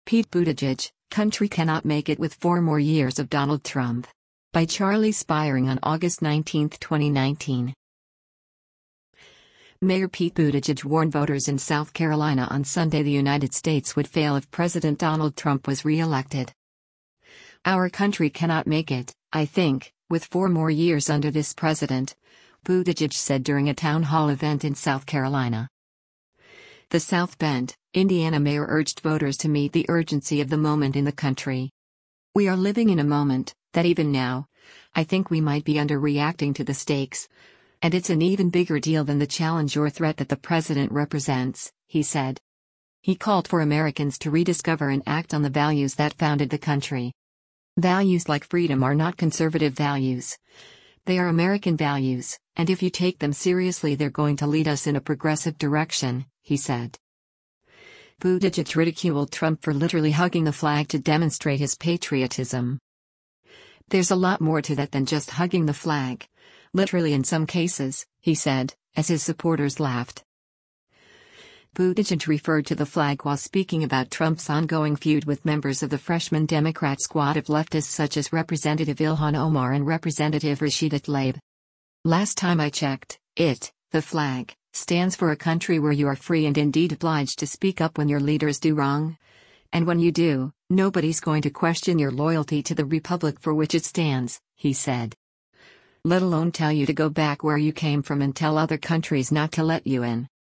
“Our country cannot make it, I think, with four more years under this president,” Buttigieg said during a town hall event in South Carolina.
“There’s a lot more to that than just hugging the flag, literally in some cases,” he said, as his supporters laughed.